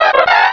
sovereignx/sound/direct_sound_samples/cries/volbeat.aif at master